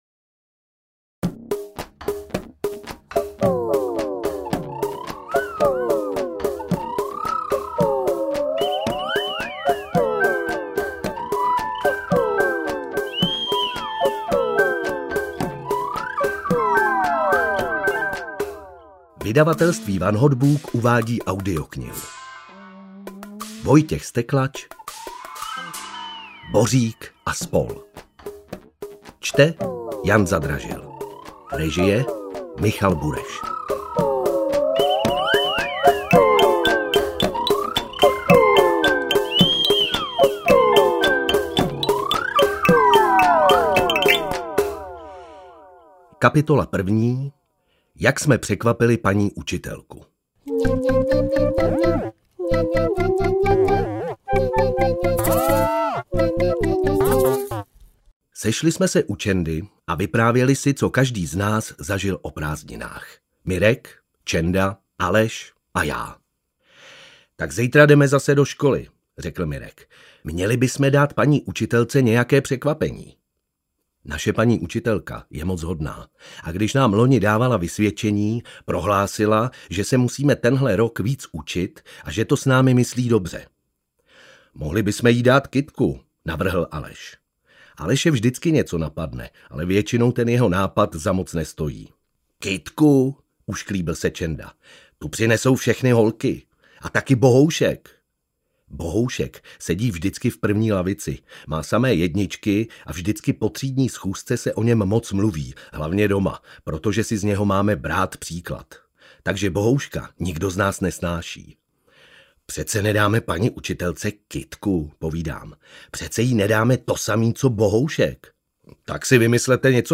AudioKniha ke stažení, 26 x mp3, délka 5 hod. 52 min., velikost 312,0 MB, česky